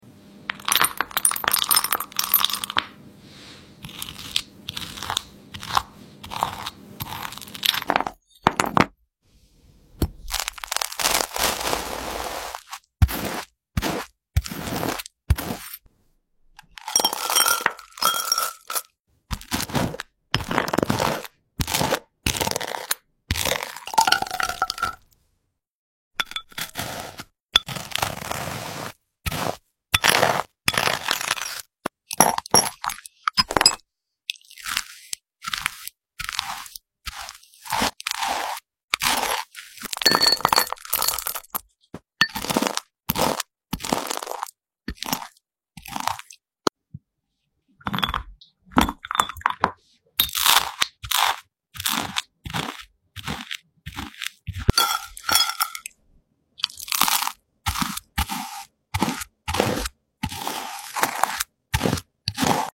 Which dragonfruit food ASMR spread